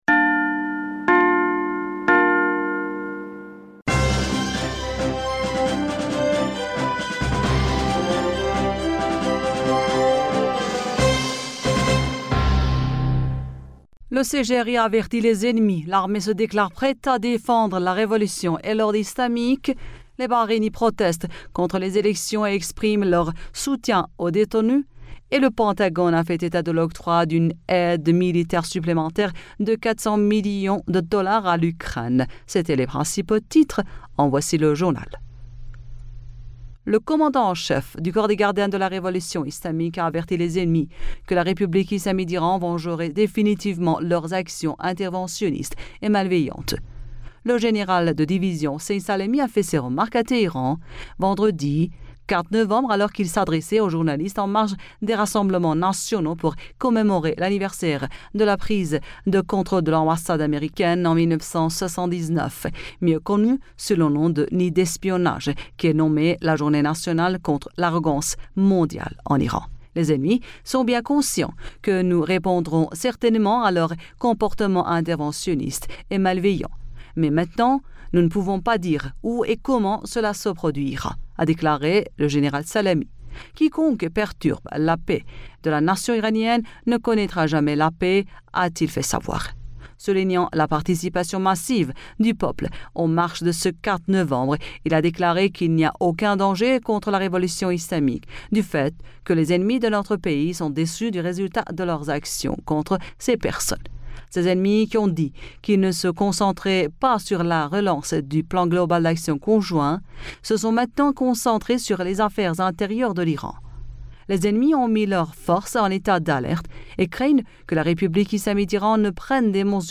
Bulletin d'information Du 05 Novembre